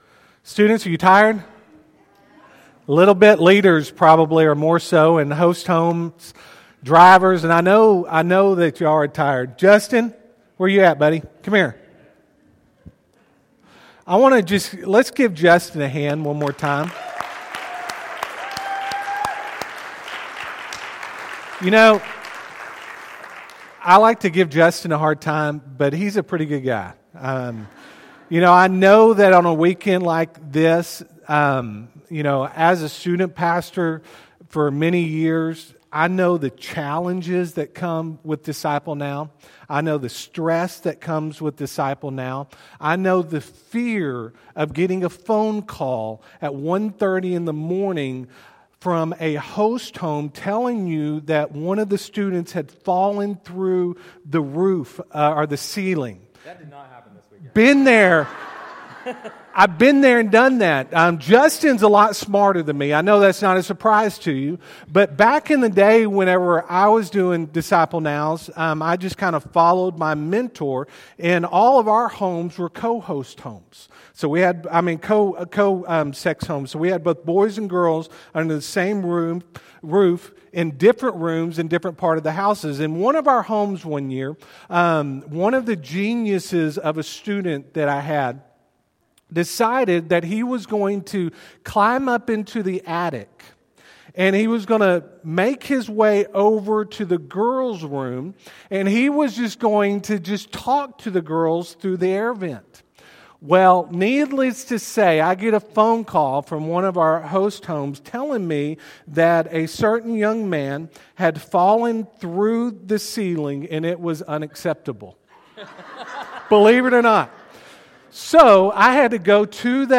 Friendship Baptist Church SERMONS